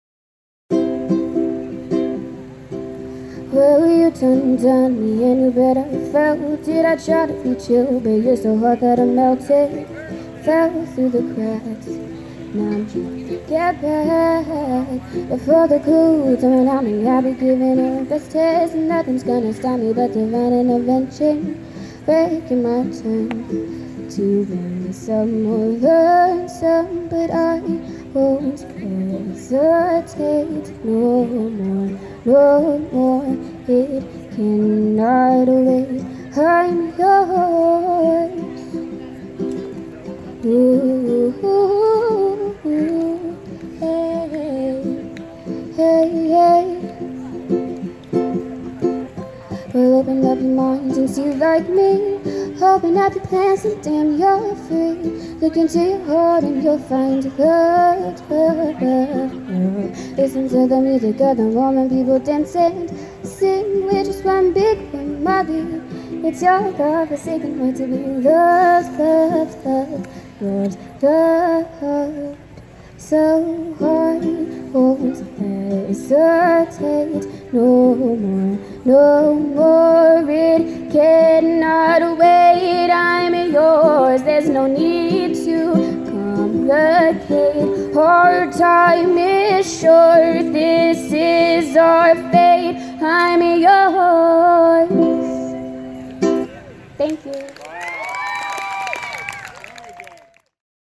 acoustic ukulele